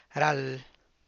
ral[ràall]